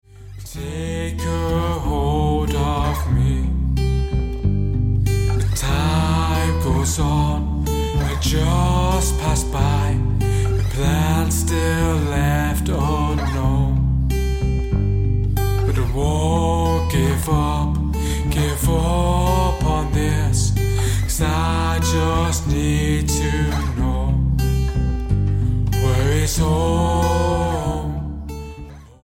STYLE: Rock
a voice reminiscent of Ian Curtis of Joy Division
backing-vocals